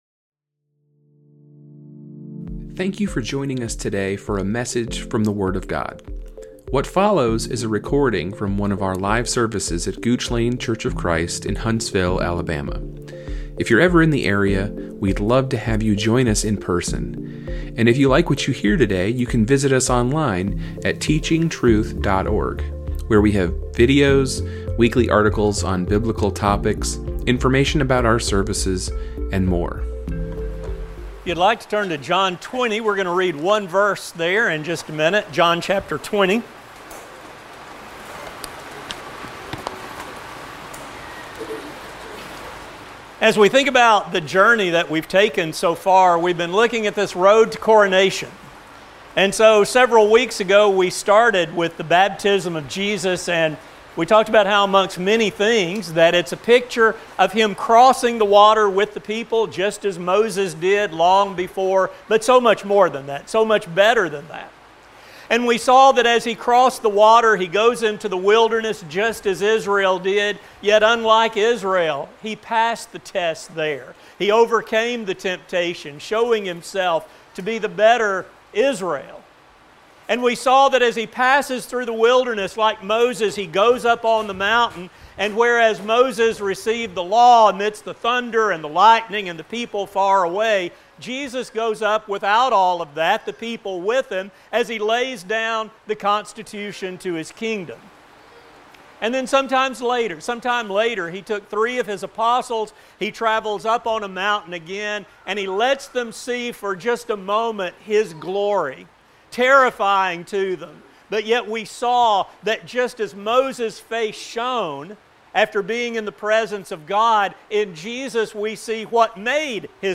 Gooch Lane Church of Christ Podcast